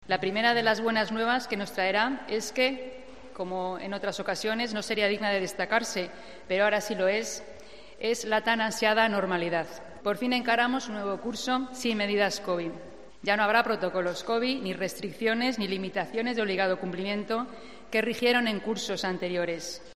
Consejera Educación. Normalidad vuelta al cole